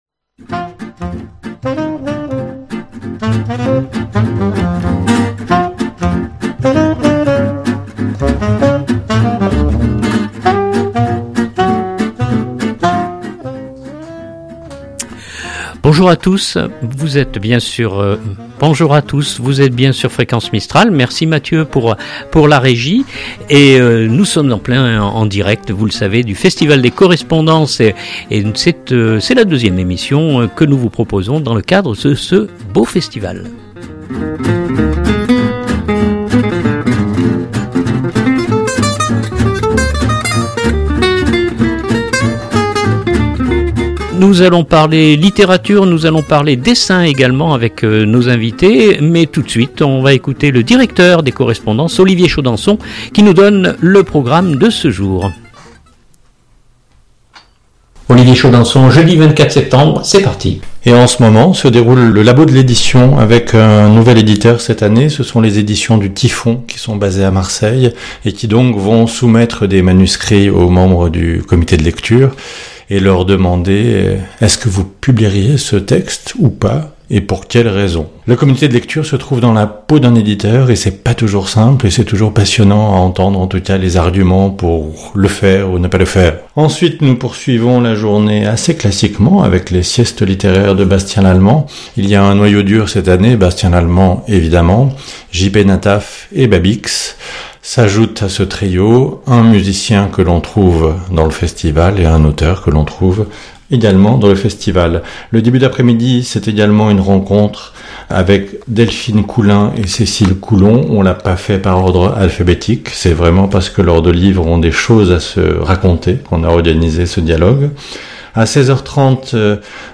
Fréquence Mistral en direct des Correspondances 2021 #2
Emission spéciale au coeur du festival manosquin...